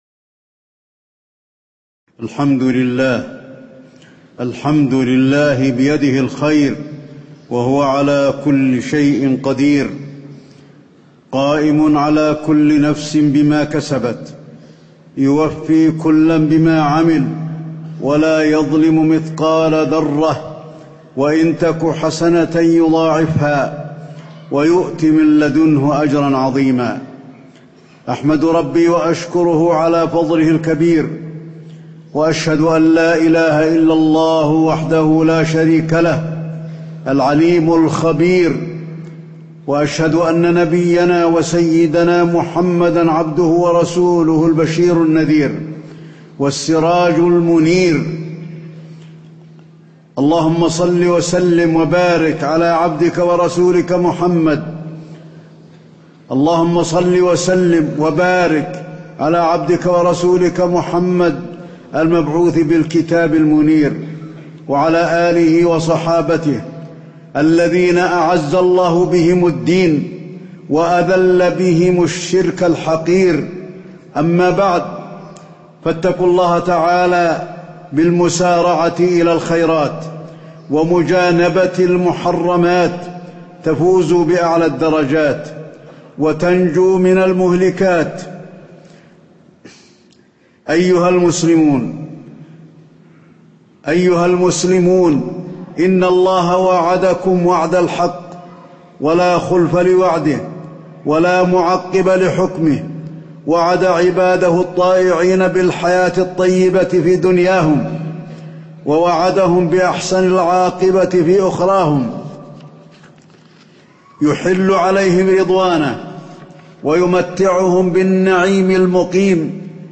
تاريخ النشر ١٥ ربيع الثاني ١٤٣٨ هـ المكان: المسجد النبوي الشيخ: فضيلة الشيخ د. علي بن عبدالرحمن الحذيفي فضيلة الشيخ د. علي بن عبدالرحمن الحذيفي صوارف العبد عن طريق الحق